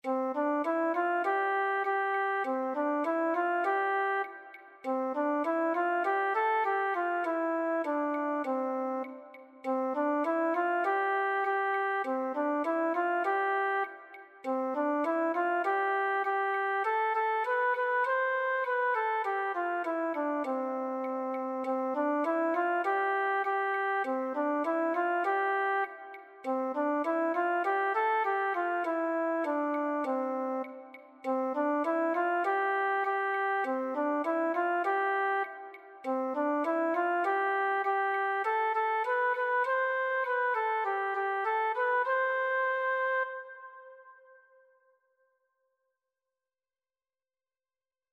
“Melodía con acompañamiento para iniciarse con la flauta diatónica„
Flauta/voz
La-escalerita-f-FLAUTA-o-VOZ.mp3